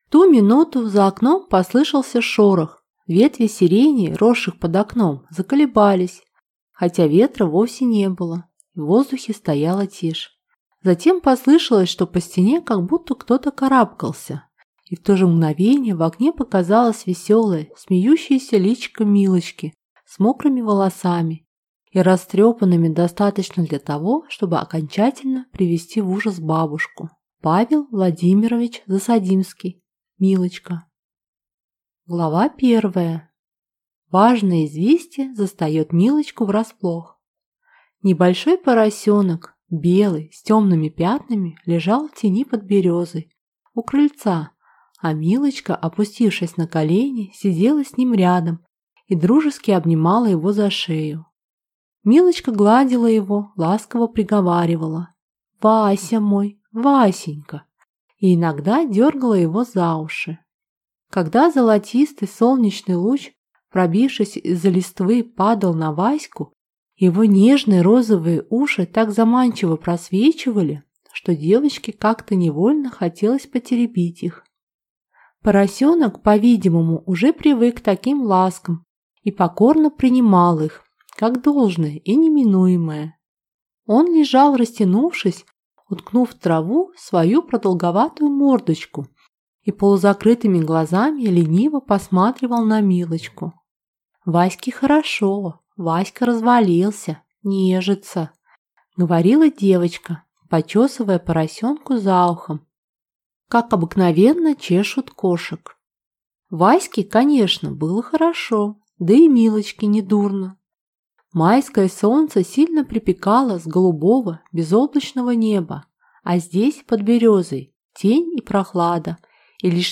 Аудиокнига Милочка | Библиотека аудиокниг